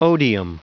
Prononciation du mot odium en anglais (fichier audio)
Prononciation du mot : odium